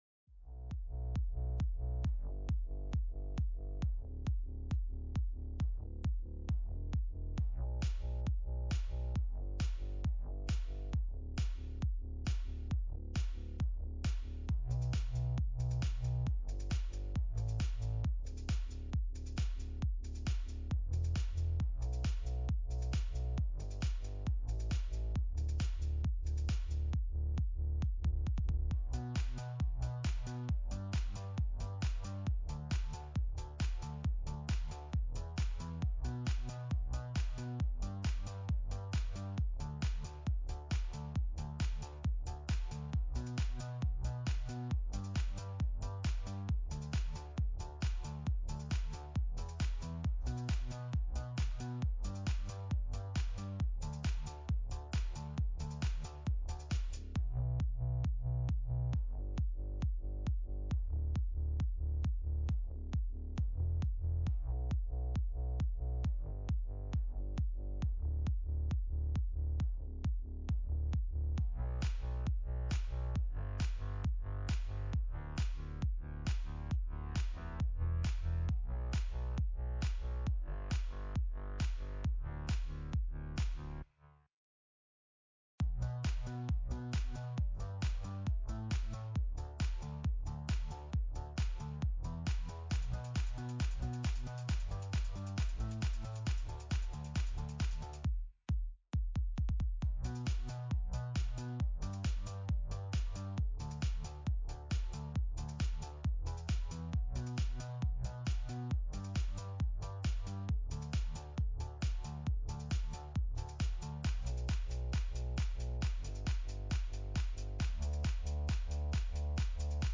Another Sunday of amazing worship from our super talented team.